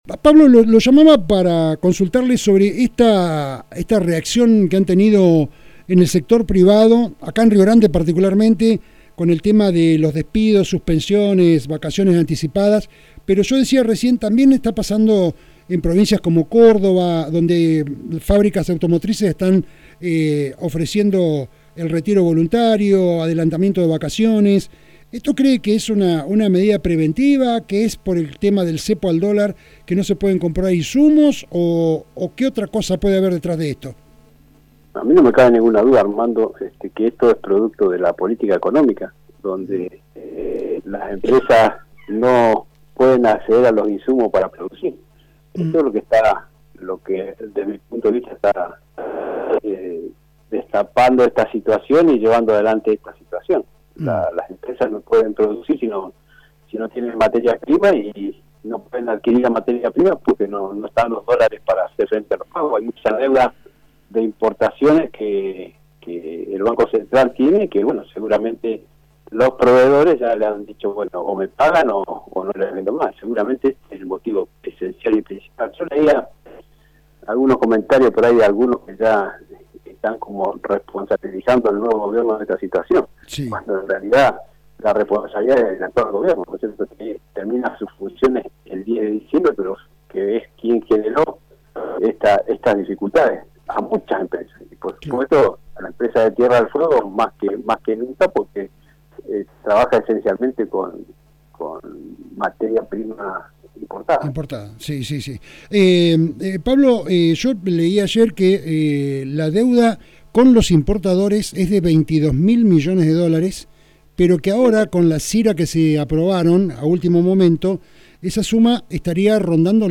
Rio Grande 03/12/2023.- Así lo expresó el Senador Pablo Blanco Integrante de la Comisión de Deuda Externa del Senado de la Nacion, en dialogo con «Resumen Económico», el legislador adelantó que después del 10 de diciembre no habrá, emisión de moneda, adelantos del tesoro o coparticipación discrecional, ni fondos que no sean los coparticipables.